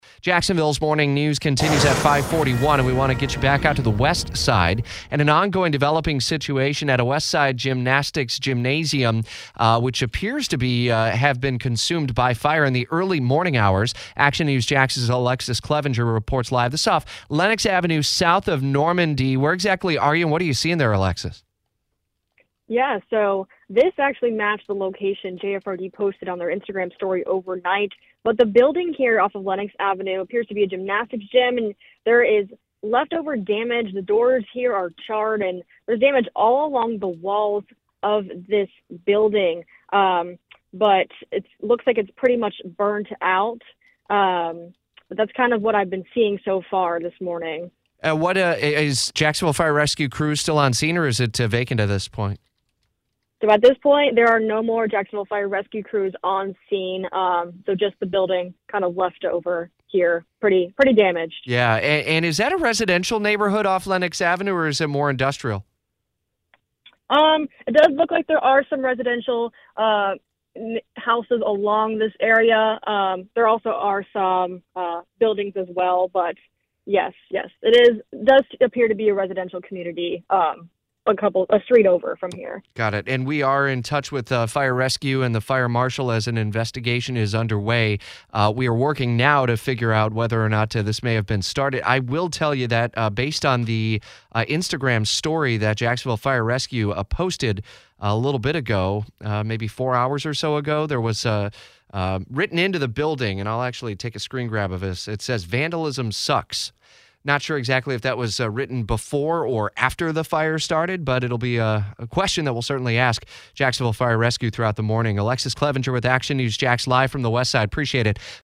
live report on fire